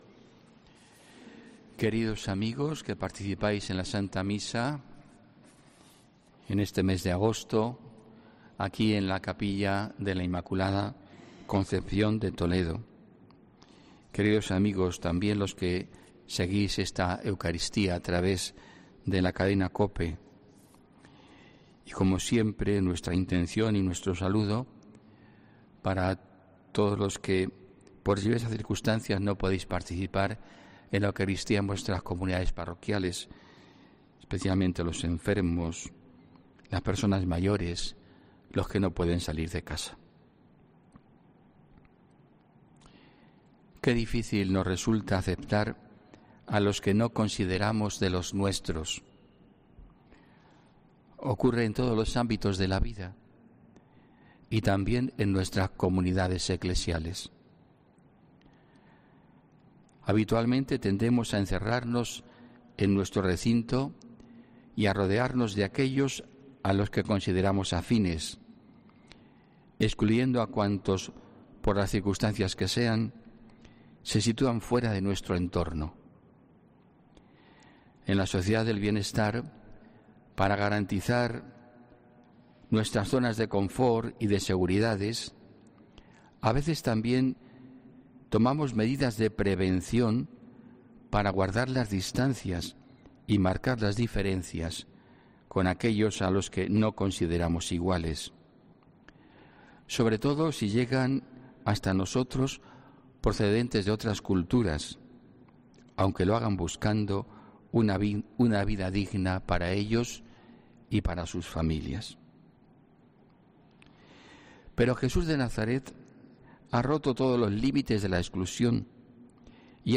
HOMILÍA 16 AGOSTO 2020